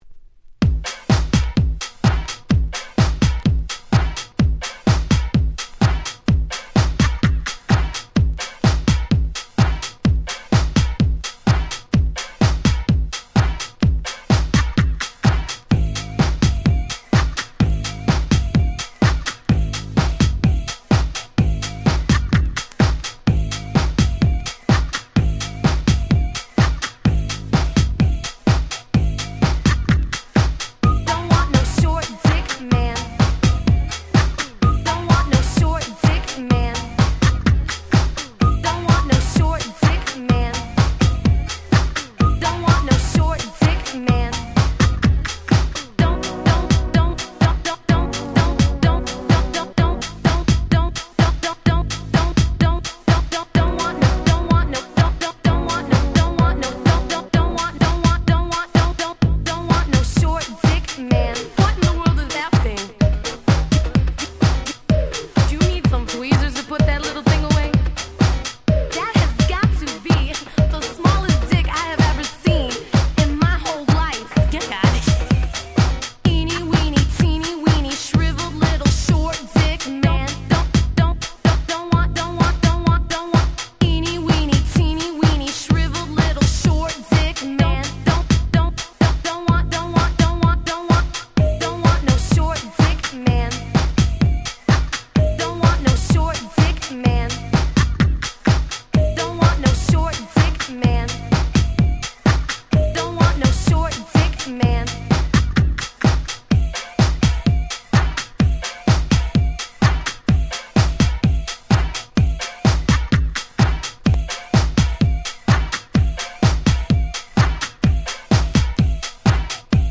【Media】Vinyl 12'' Single
自分が当時好んで使用していた曲を試聴ファイルとして録音しておきました。